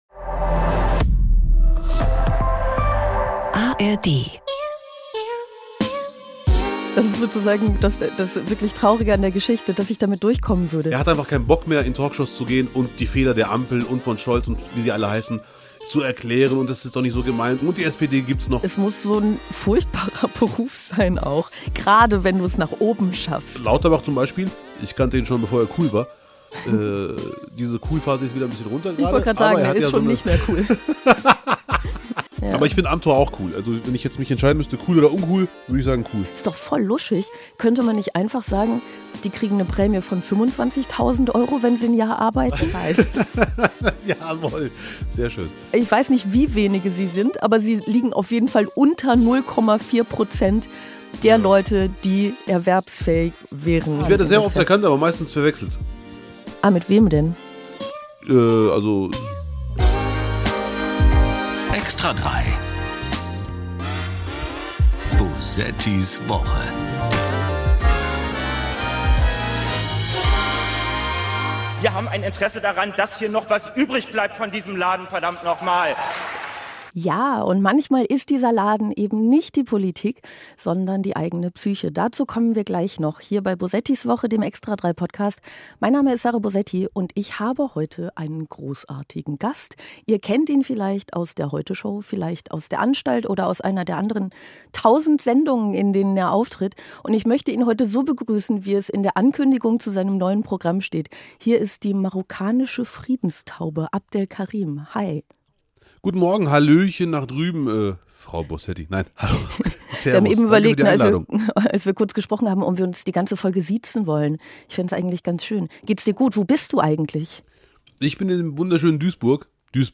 1 Lesestunde
Sie spricht über das Deutschland im Hier und Jetzt, über Geschichtsnarrative deutscher Schuld und den Mut zur Freiheit, über politische Ausgrenzung und über Möglichkeiten der Erlösung. Im Anschluss liest sie Auszüge aus ihrer Deutschen Tetralogie.…